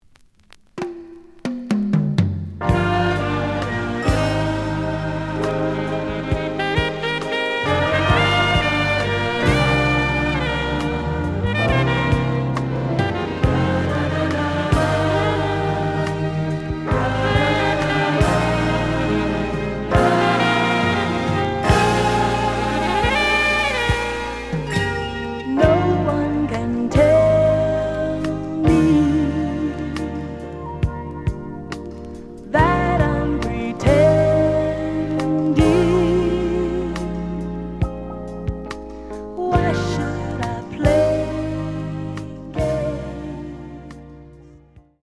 試聴は実際のレコードから録音しています。
●Genre: Disco
●Record Grading: VG+~EX- (両面のラベルに若干のダメージ。多少の傷はあるが、おおむね良好。)